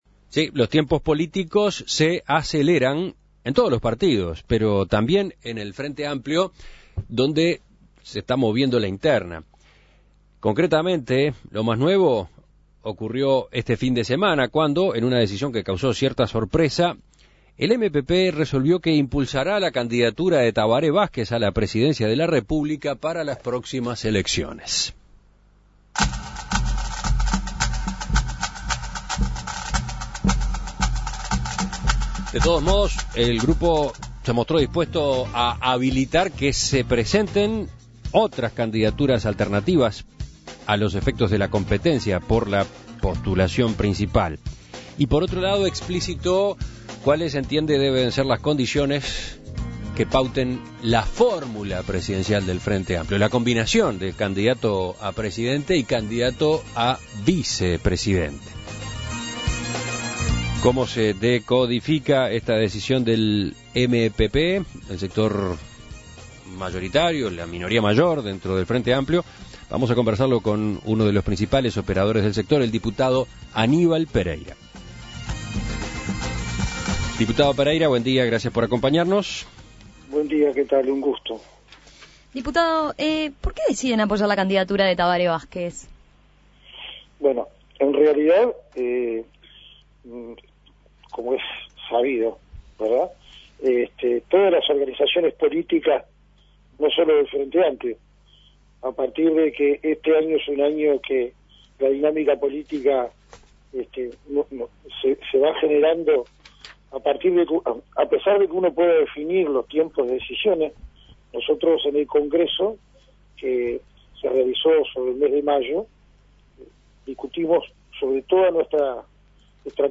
Escuche la entrevista a Aníbal Pereyra